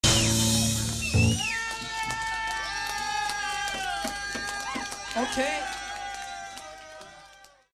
Flamman August 18, 1979
This was possible due to the Tandberg built in mixer of two mics with stereo line input.
audience2.mp3